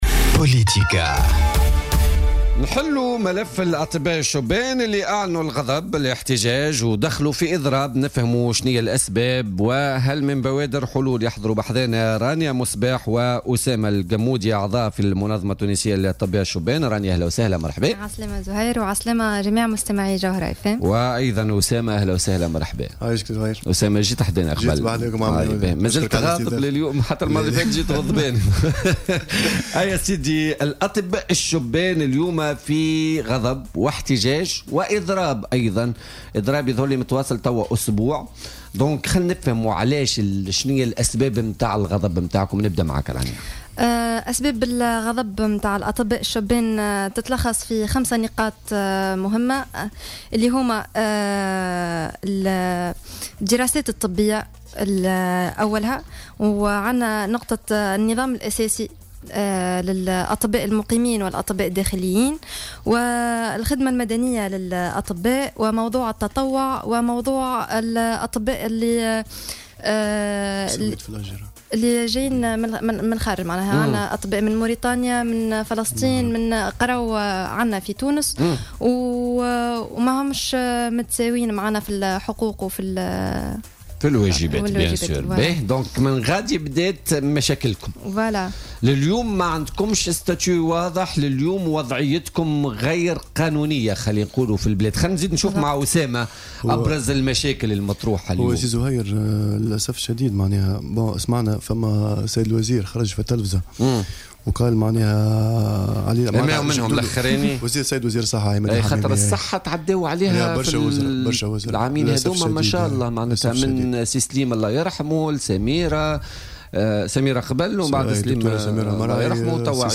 استضاف برنامج بوليتيكا على الجوهرة اف أم اليوم الاثنين عضوين من المنظمة...